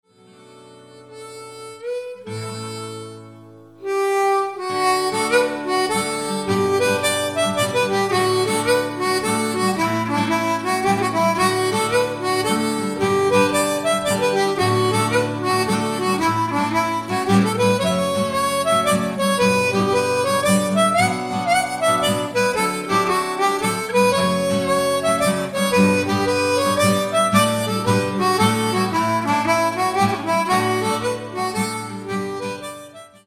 air, slip jig
• Diatonic harmonicas
Acoustic guitar, bass guitar, fiddle, mandolin, vocals